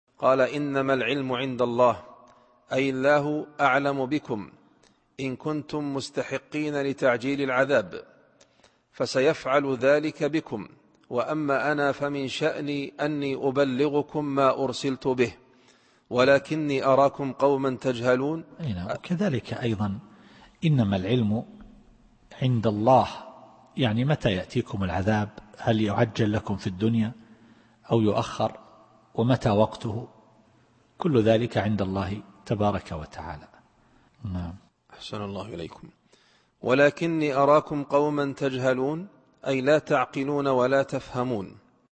التفسير الصوتي [الأحقاف / 23]